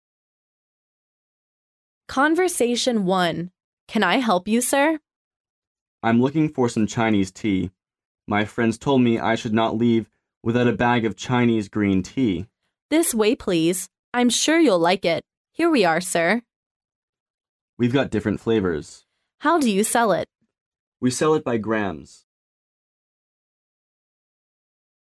Conversation 1